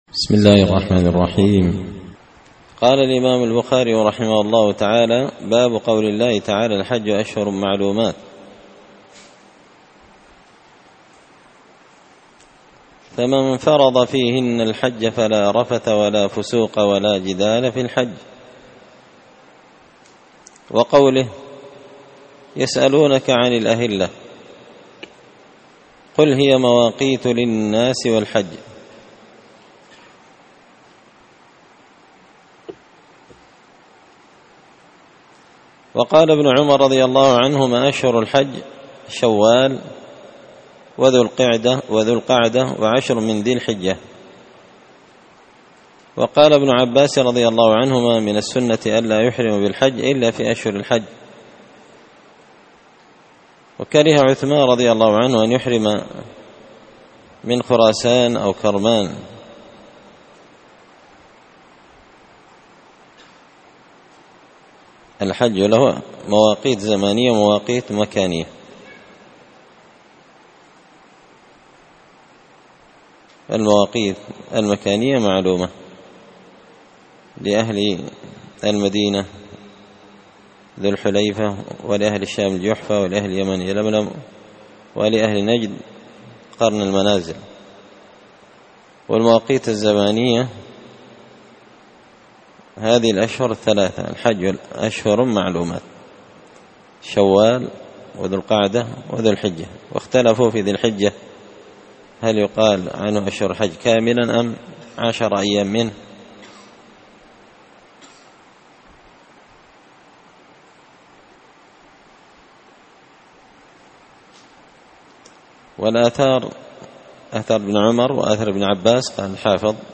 كتاب الحج من شرح صحيح البخاري – الدرس 26
دار الحديث بمسجد الفرقان ـ قشن ـ المهرة ـ اليمن